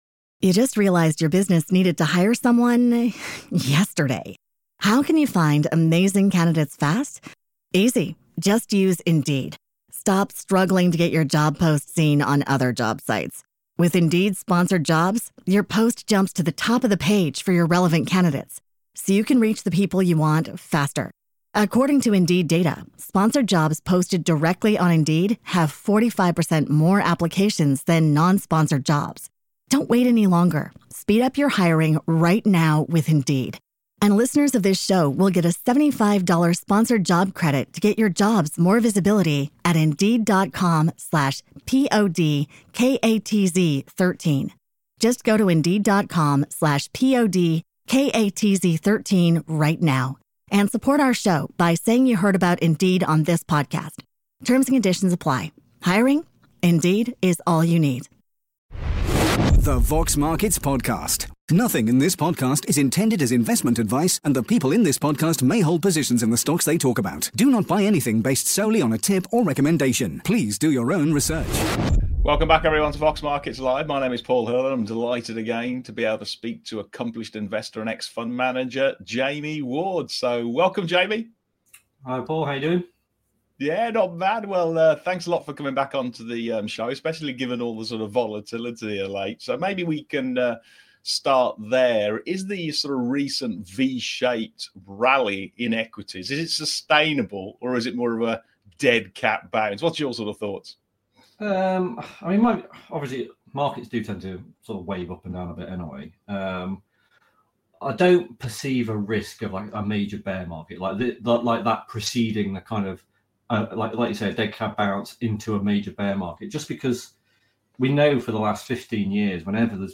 In this week’s live Exchange